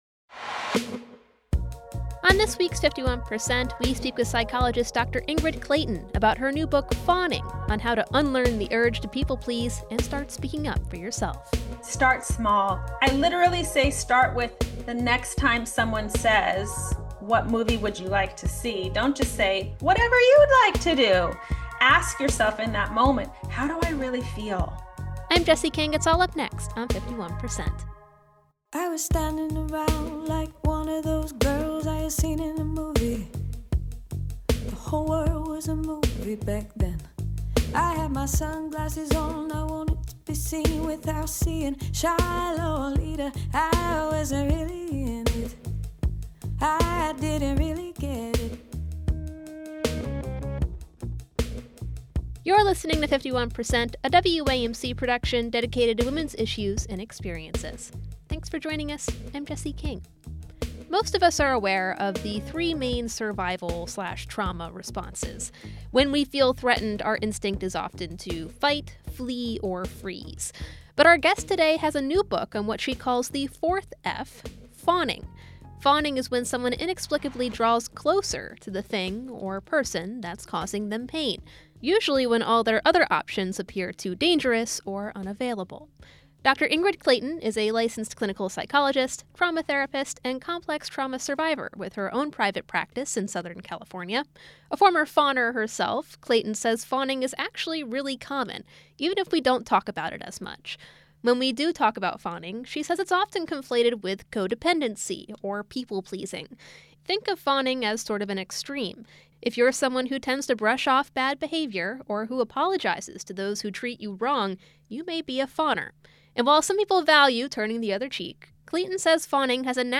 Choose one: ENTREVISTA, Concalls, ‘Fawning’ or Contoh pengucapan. ‘Fawning’